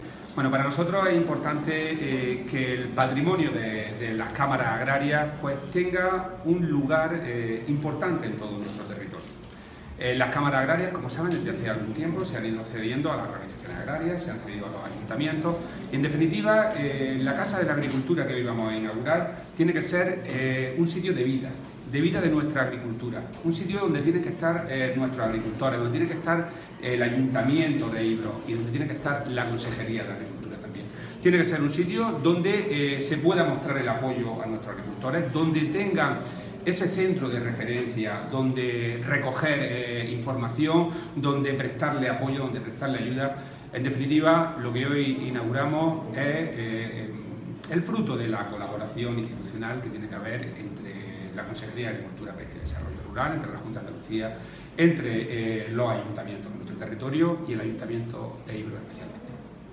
Declaraciones de Rodrigo Sánchez sobre la Casa de la Agricultura de Ibros (Jaén)